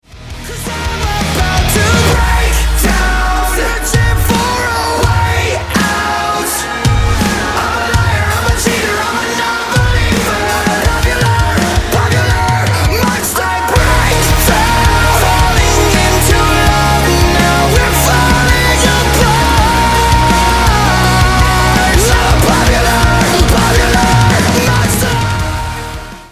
Pop & Rock
Hard Rock